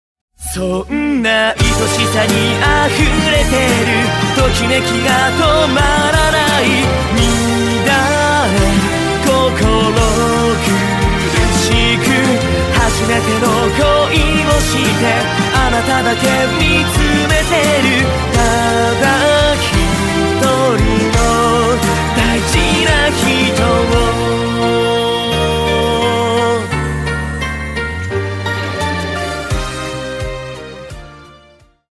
キャラクターソング
春の木漏れ日のような穏やかな曲調。